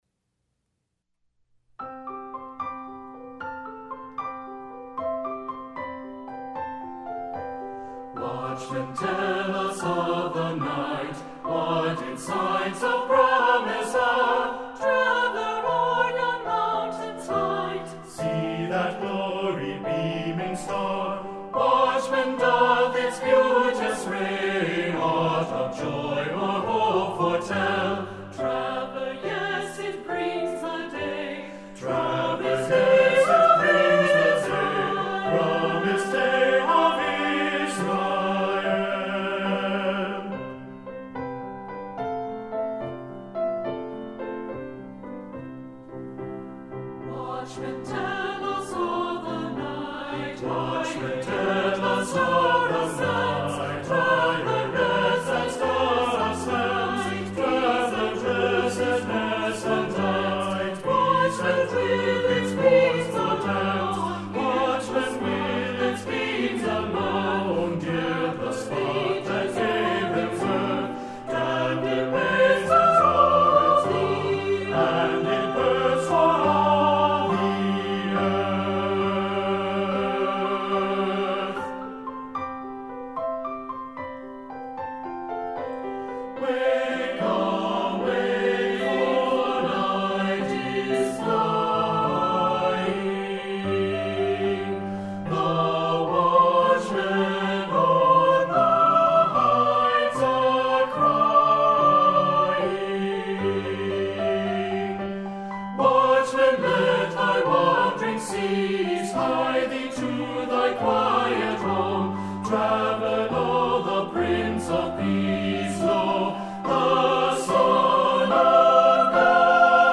Choral Concert/General
Looking for an accessible 2-part mixed advent anthem.
2 Part Mix